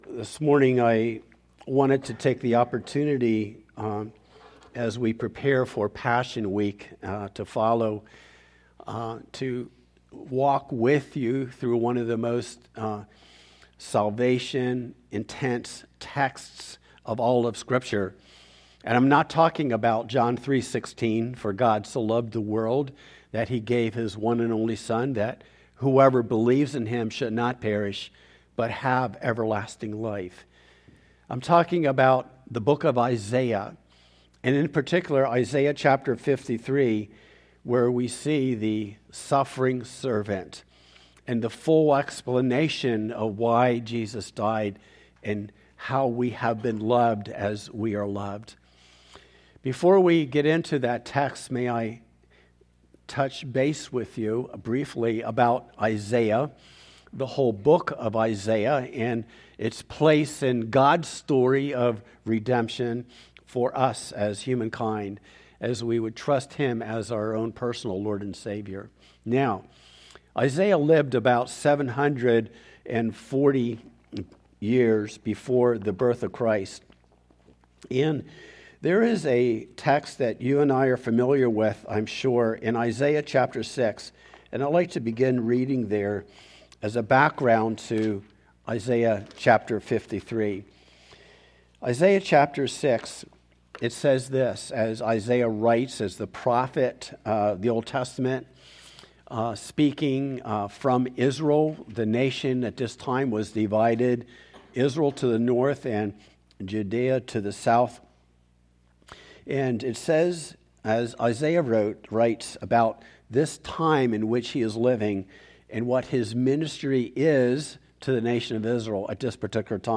3-22-26-Sermon-Jesus-the-Suffering-Servant.mp3